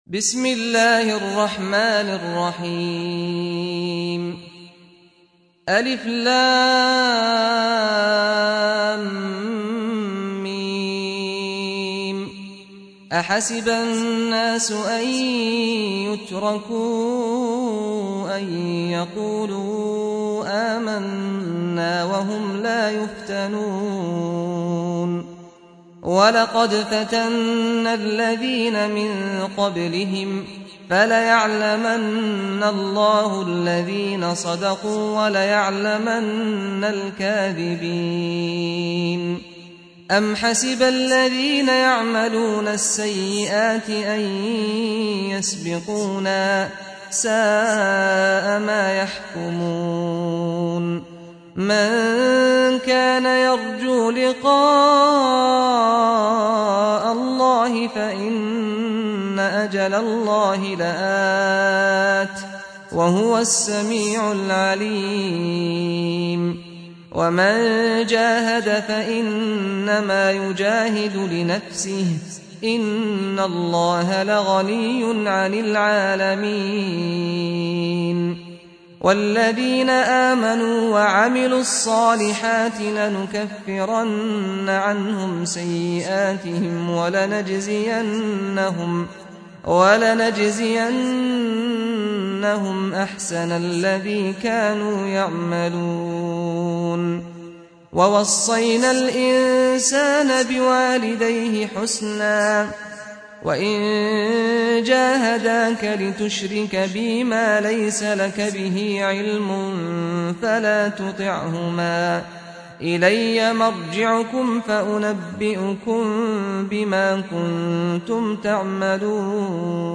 سُورَةُ العَنكَبُوتِ بصوت الشيخ سعد الغامدي